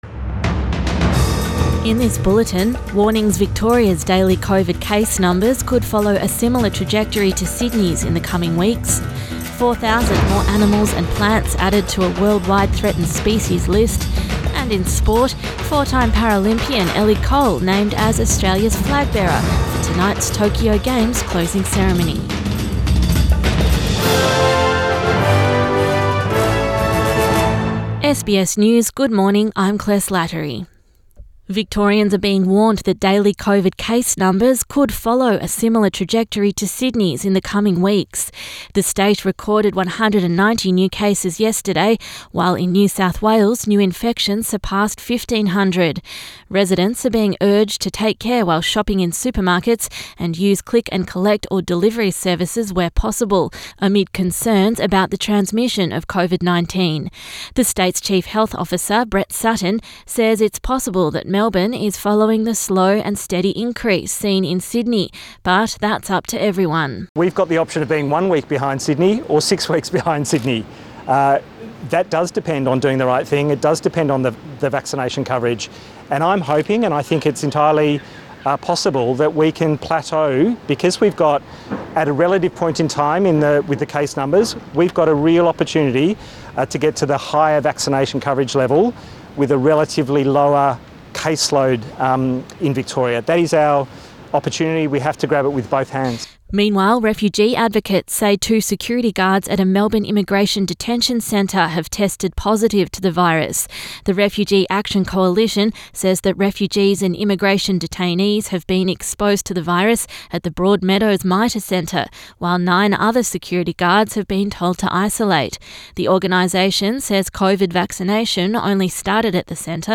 AM bulletin 5 September 2021